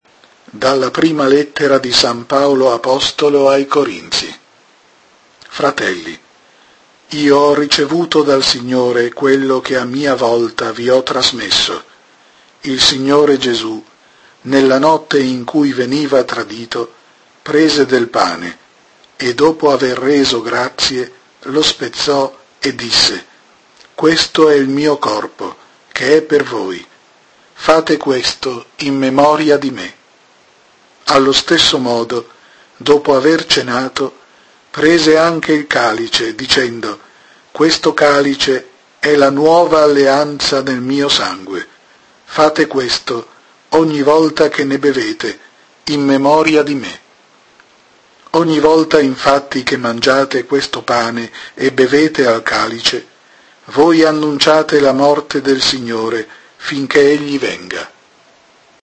Seconda lettura